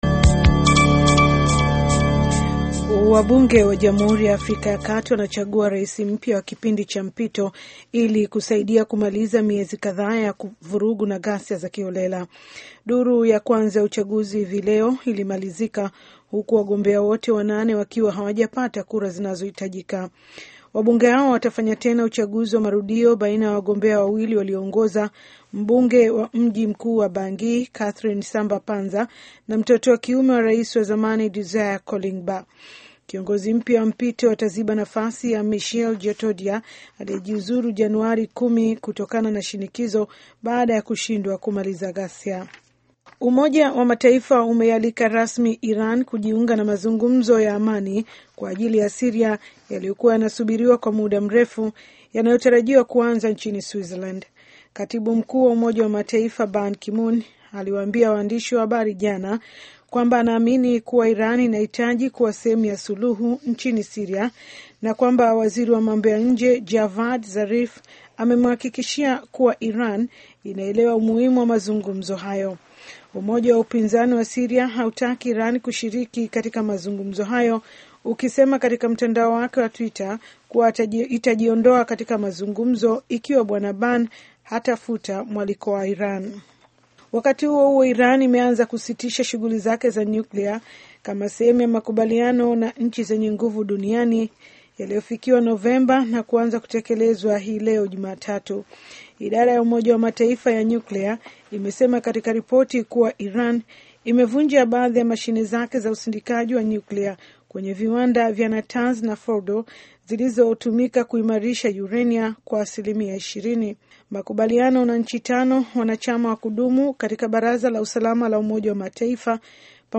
Taarifa ya Habari VOA Swahili - 7:06